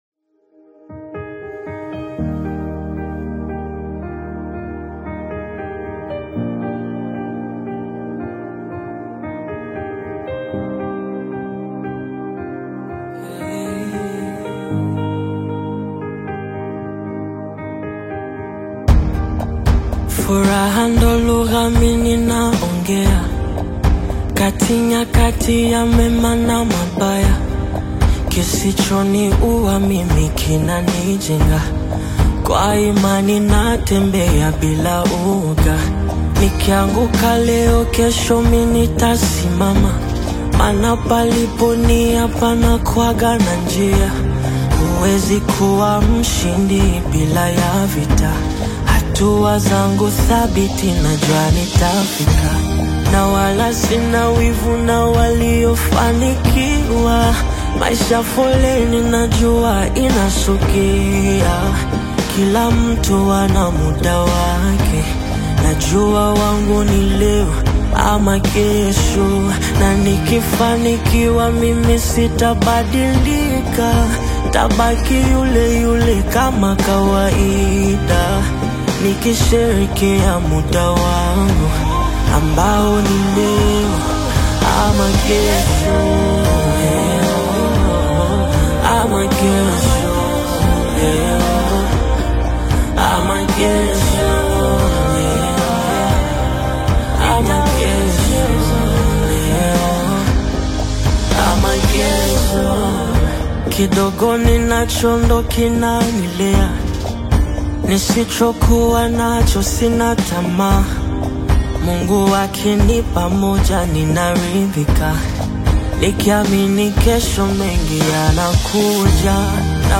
Latest Tanzania Afro-Beats Single (2026)
Genre: Afro-Beats